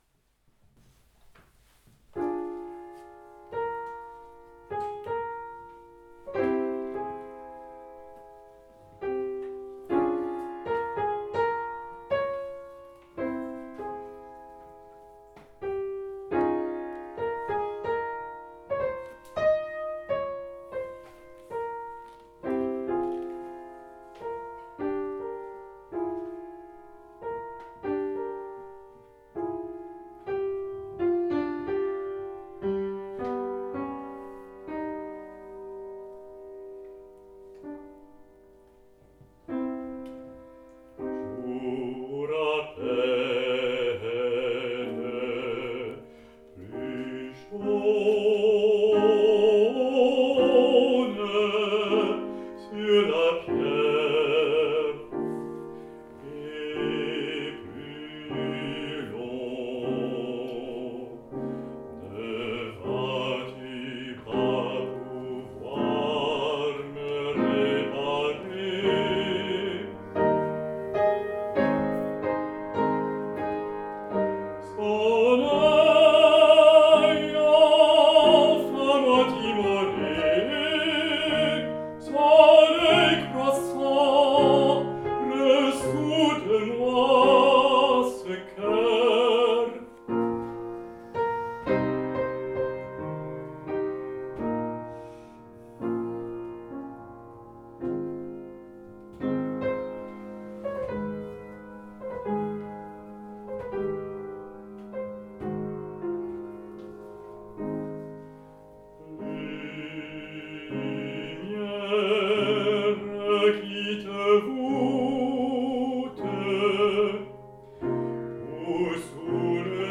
Baryton